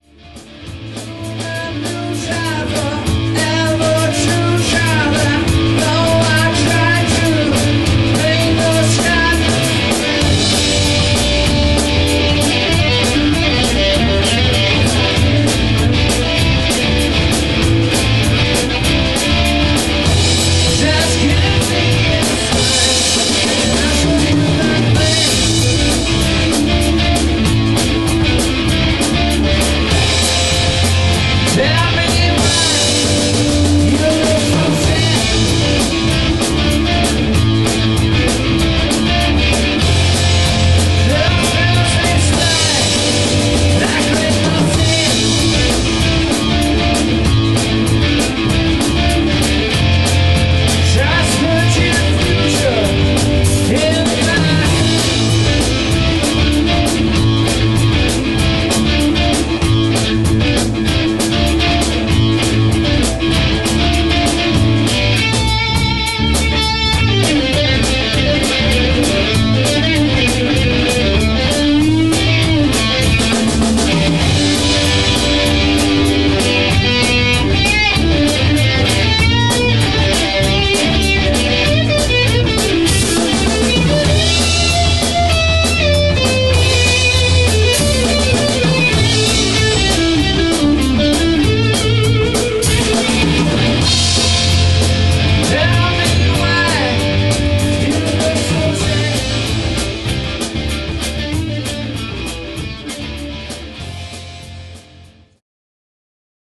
rock and hard rock
vocals, guitar
bass, backing vocals
drums, percussion
Mitschnitte aus dem Proberaum